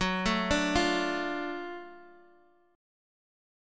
GbmM7#5 chord